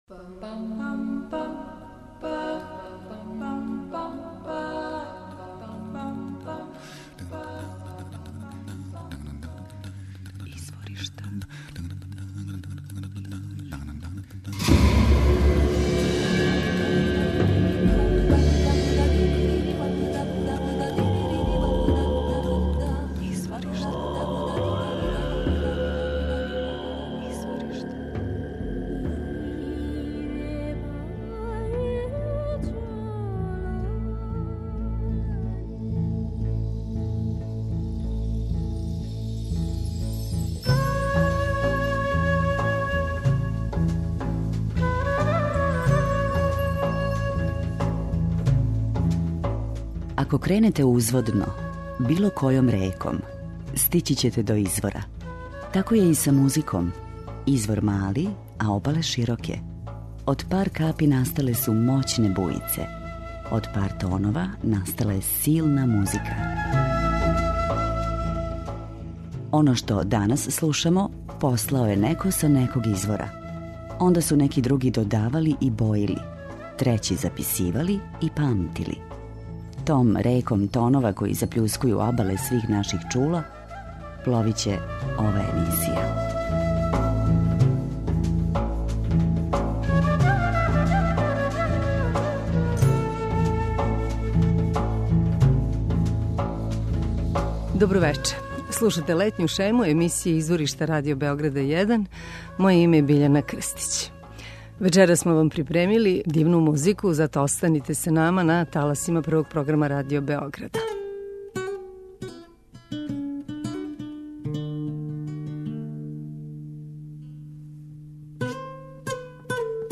Они који још увек нису отпутовали или остају преко лета у Београду моћи ће да уживају у врелим ритмовима и мелодијама из читавог света које смо одабрали у летњој шеми емисије Изворишта.
преузми : 28.67 MB Изворишта Autor: Музичка редакција Првог програма Радио Београда Музика удаљених крајева планете, модерна извођења традиционалних мелодија и песама, културна баштина најмузикалнијих народа света, врели ритмови... У две речи: World Music.